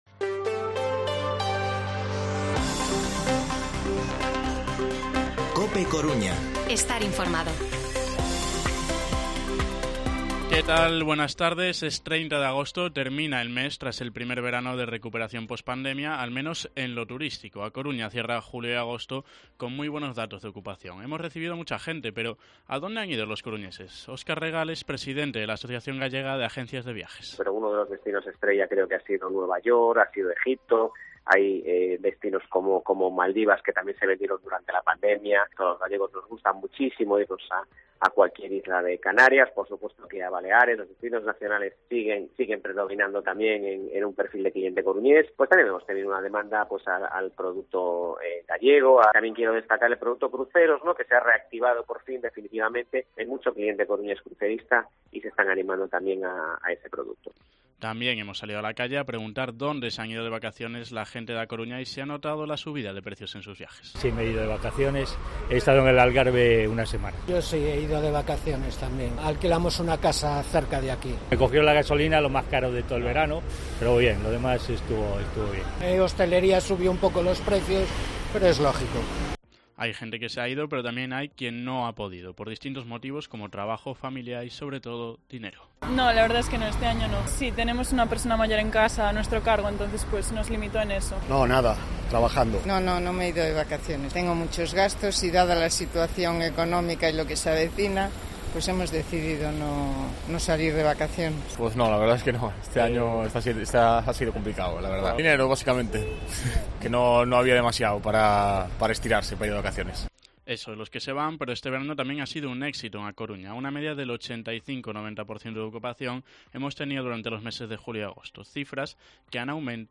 Informativo Mediodía COPE Coruña martes, 30 de agosto de 2022 14:20-14:30